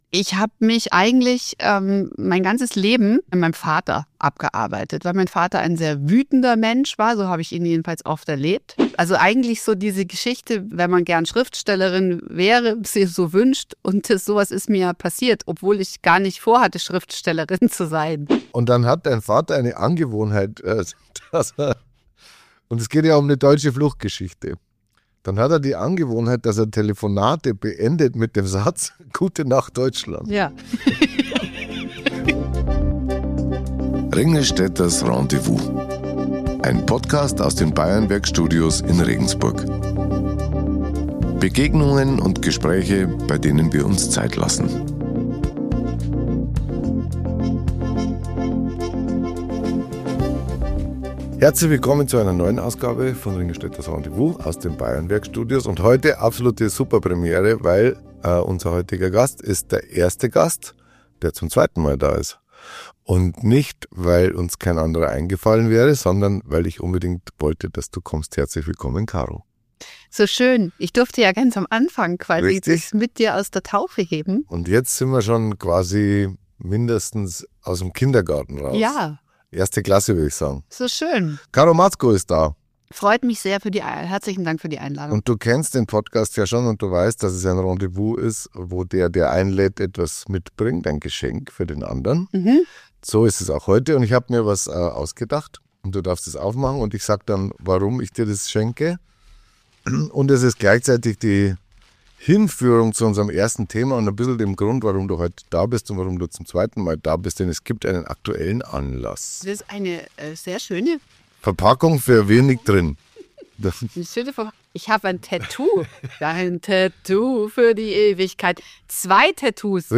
In dieser Folge spricht Hannes Ringlstetter mit der Journalistin, Moderatorin und Autorin Caro Matzko – über ihr neues Buch, ihren Vater und die Frage, wie sehr Herkunft und Familie uns prägen.
Außerdem geht es um kreative Freiheit, das Schreiben, den Abschied vom Fernsehen und die Kunst, im eigenen Tempo zu leben. Ein tiefes, ehrliches und zugleich warmherziges Gespräch über Familie, Freundschaft und das Ankommen bei sich selbst.